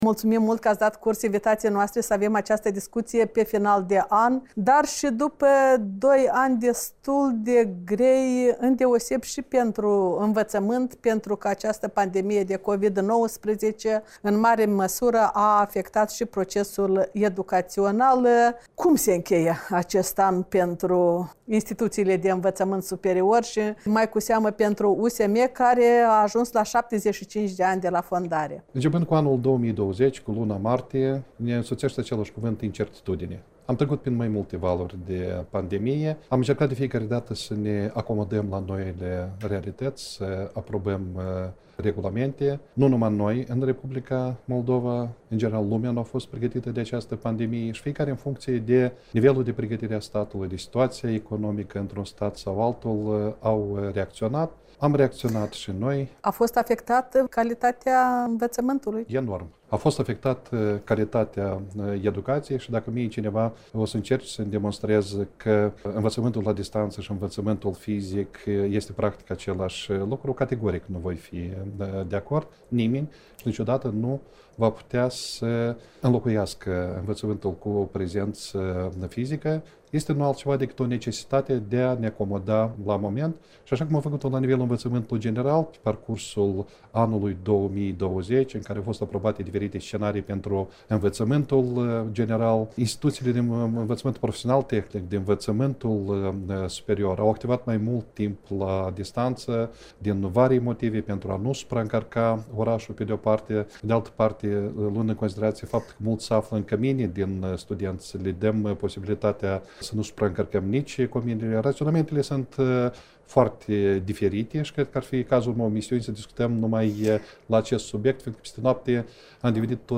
Interviu de bilanț